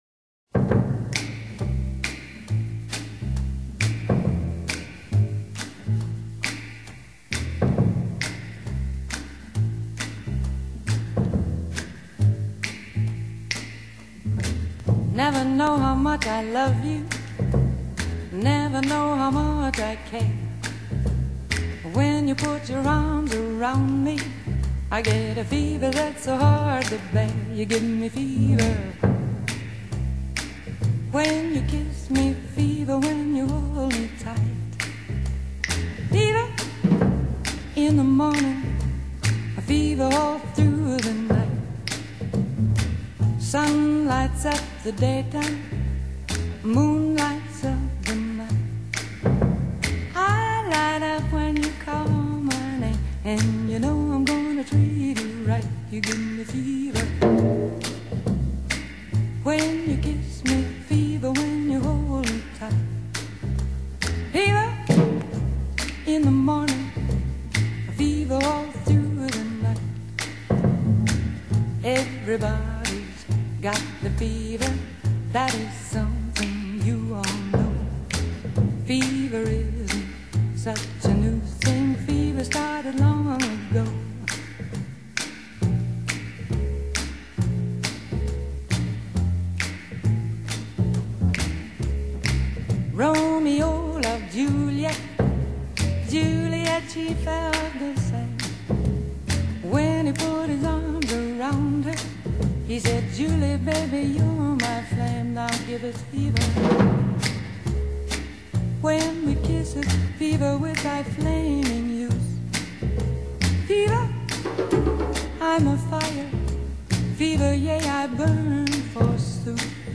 Vocal Jazz Singer